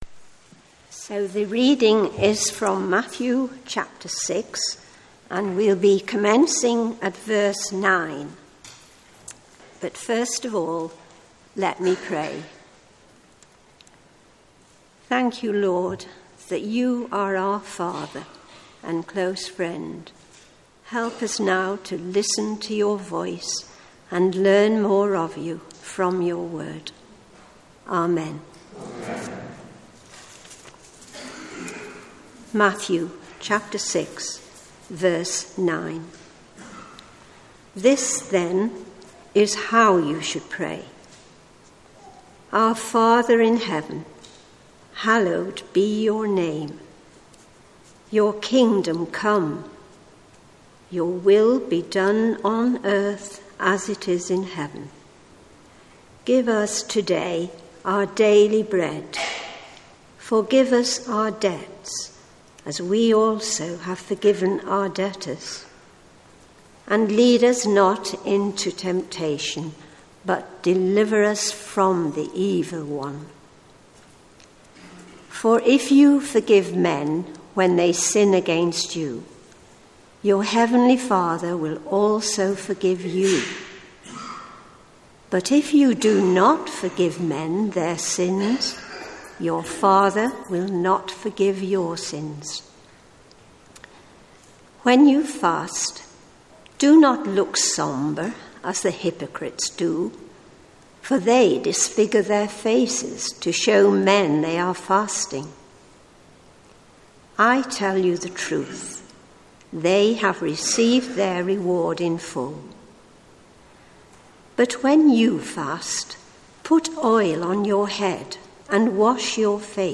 Sermons Archive - Page 87 of 188 - All Saints Preston